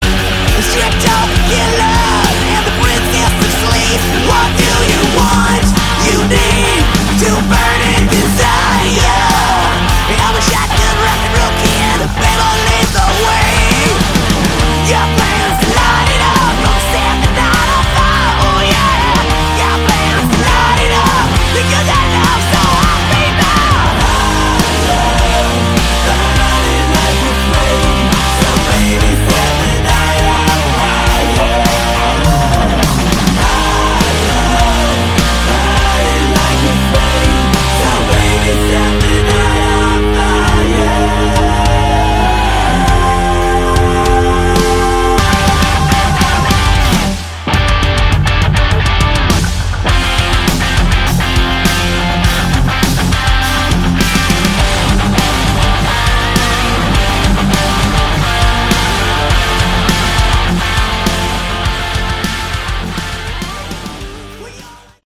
Category: 80s Hard Rock